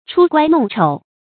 出乖弄丑 注音： ㄔㄨ ㄍㄨㄞ ㄋㄨㄙˋ ㄔㄡˇ 讀音讀法： 意思解釋： 出丑，丟臉。